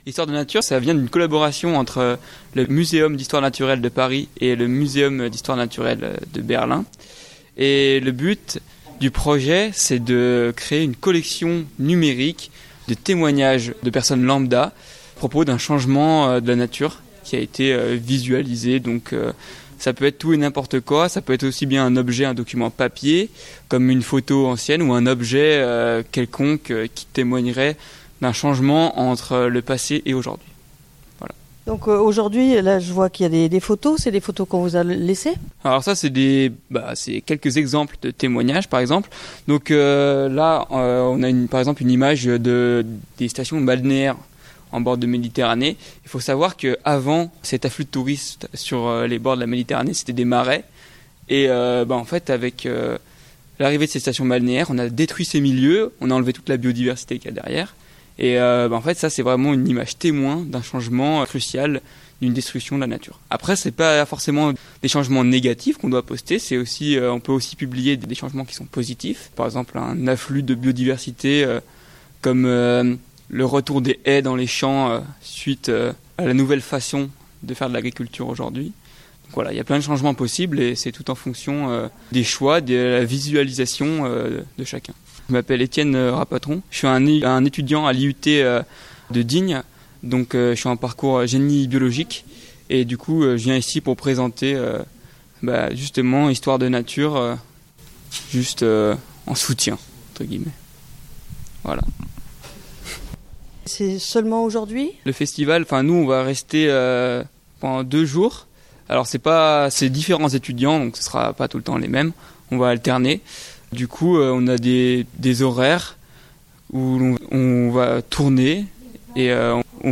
Dans le hall de la médiathèque